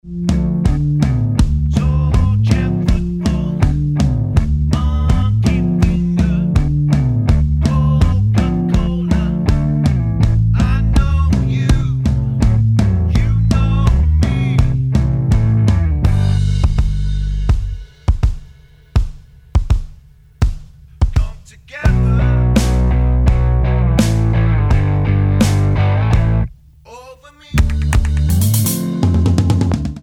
--> MP3 Demo abspielen...
Tonart:D mit Chor